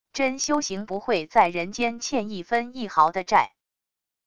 真修行不会在人间欠一分一毫的债wav音频生成系统WAV Audio Player